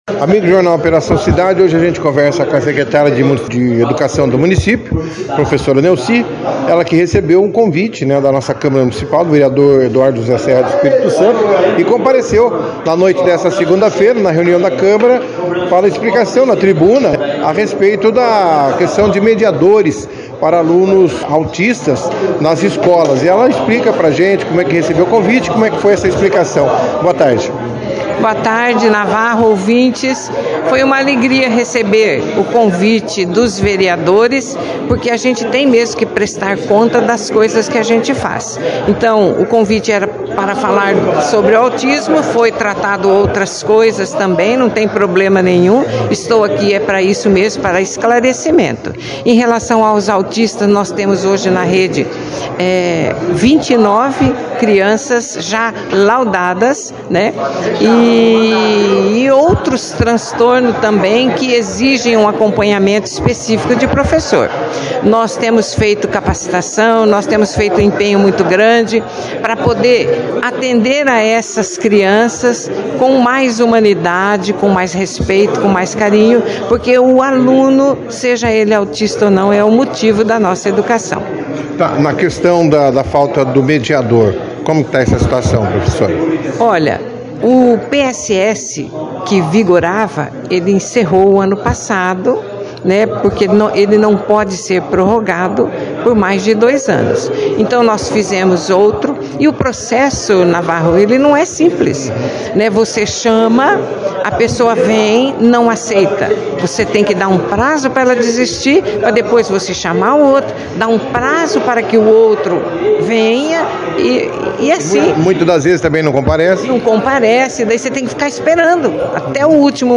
A sessão foi destaque da 2ª edição do jornal “Operação Cidade” desta terça-feira, dia 20 de fevereiro de 2024, com a participação da Secretária Municipal de Educação e Cultura, Professora Nelci Maria Martins de Queiróz, além dos vereadores Dr. Eduardo e Thiago Faxino, que discorreram sobre a sessão que ocorrerá nesta quarta-feira, 21 de fevereiro, às 17 horas, sobre a Comissão Parlamentar Processante (CPP), instaurada para investigar denúncias contra o prefeito Jaelson da Matta.